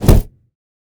Massive Punch C.wav